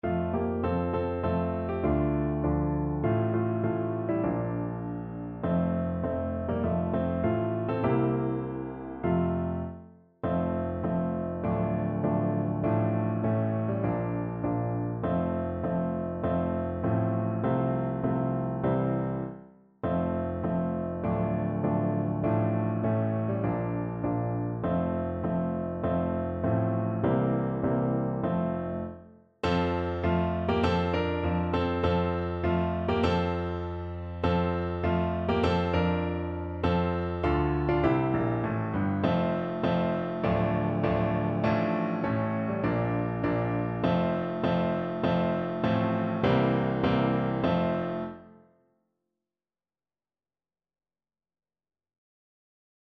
Play (or use space bar on your keyboard) Pause Music Playalong - Piano Accompaniment transpose reset tempo print settings full screen
F major (Sounding Pitch) (View more F major Music for Trombone )
Moderato